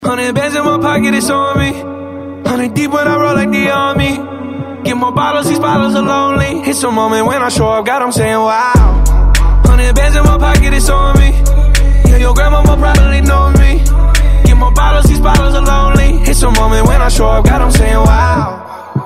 Категория: Рэп рингтоны